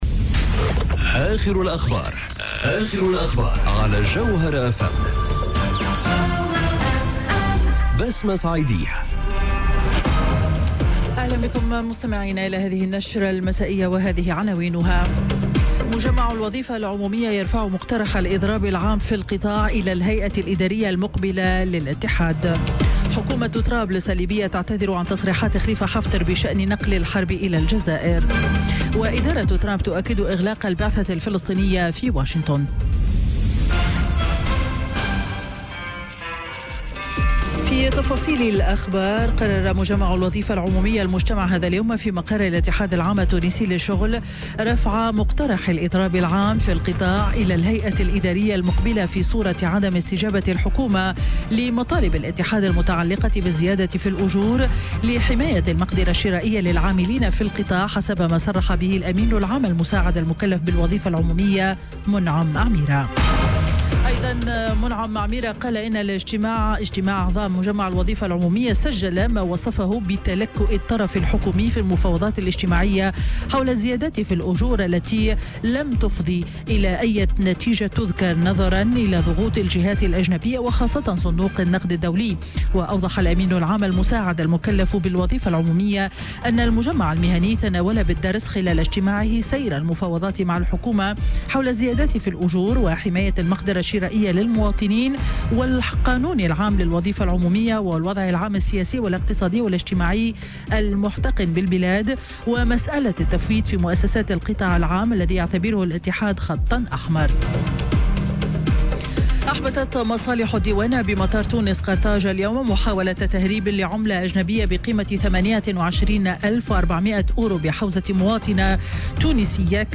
نشرة أخبار السابعة مساء ليوم الاثنين 10 سبتمبر 2018